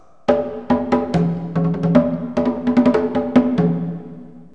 congas.mp3